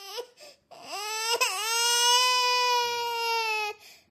cf_baby_crying.ogg